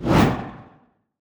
player-swoosh.ogg